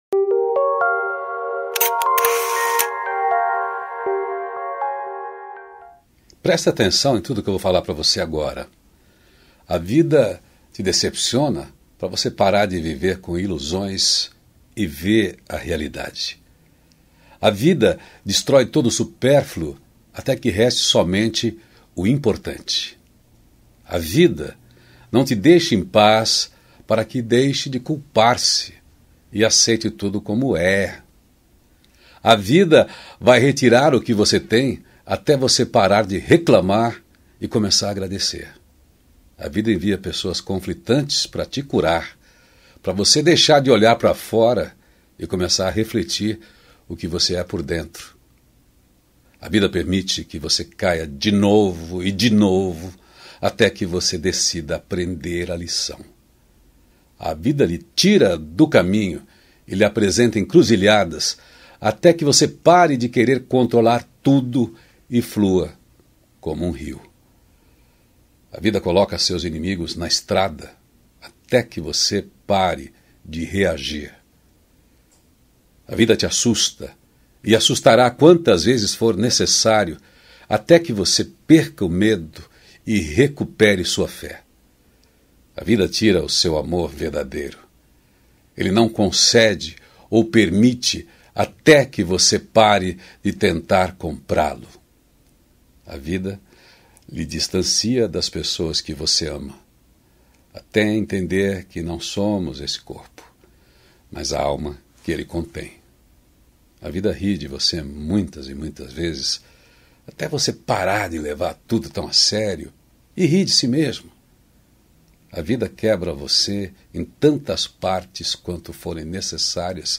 A Vida – texto de Bert Hellinger